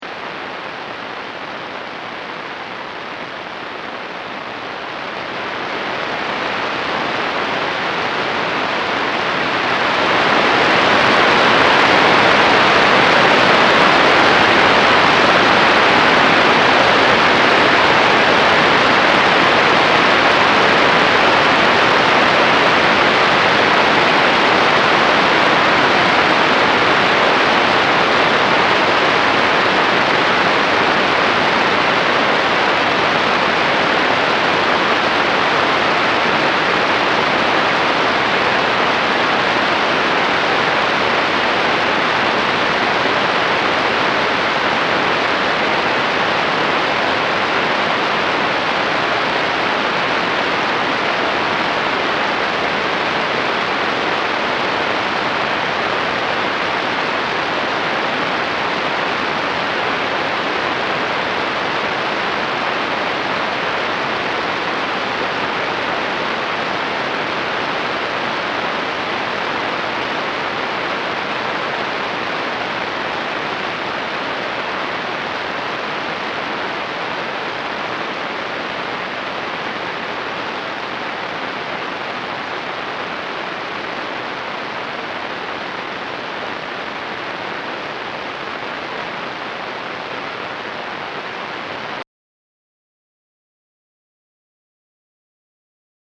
Bu etkinliğin yanında, dün Güneş'in yüksek atmosfer bölgesinde hareket eden elektronların oluşturdukları radyo dalgaları atımı da gözlendi.
Radyo dalgalarının ses karşılıklarını da